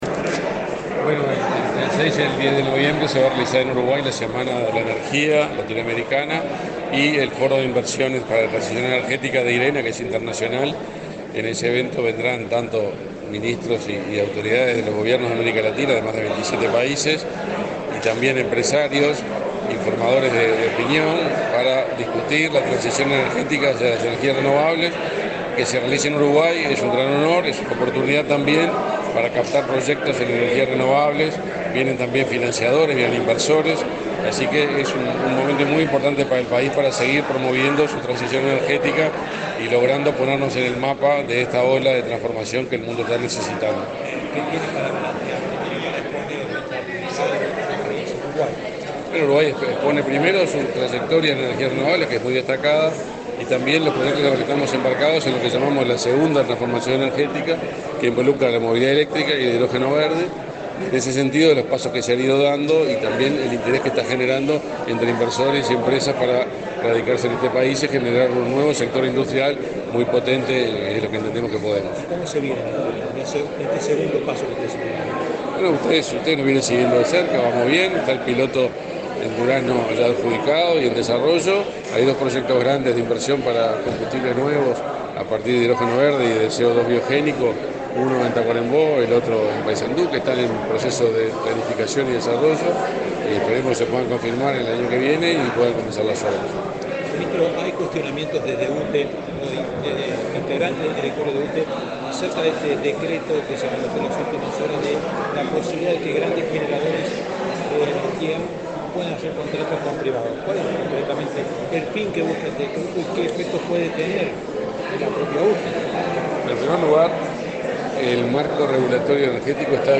Declaraciones del ministro de Industria, Omar Paganini
Este jueves 17 en la Torre Ejecutiva, el ministro de Industria, Omar Paganini, dialogó con la prensa, luego de participar en el lanzamiento de la VIII